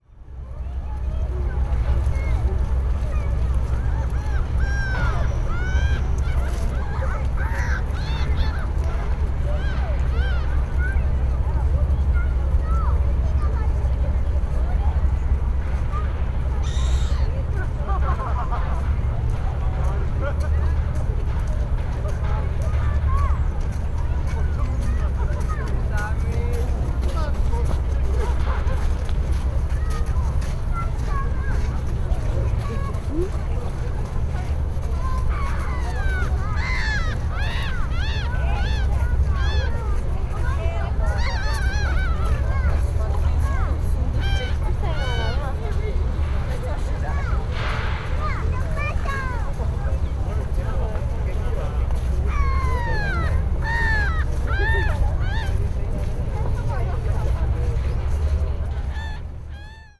Jardin des Tuileries, Paris, 12/11/2023
Alors on a retraversé le jardin sous la pluie — je m’étais arrêté trois minutes au bord du bassin pour prendre un son, et le photographier la grande roue au fond.